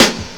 Medicated Snare 34.wav